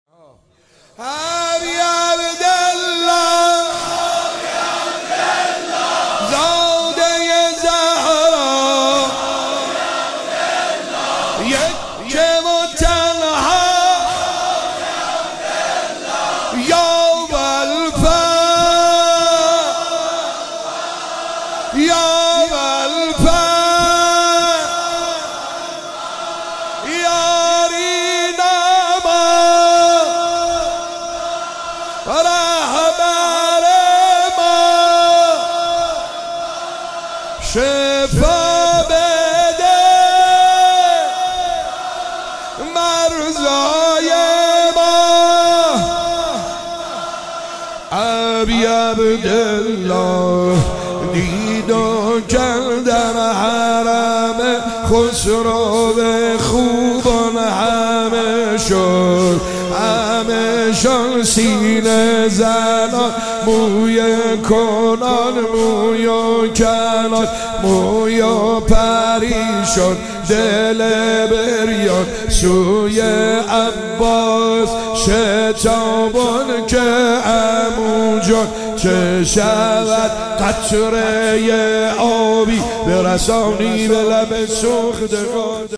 شب اول فاطمیه دوم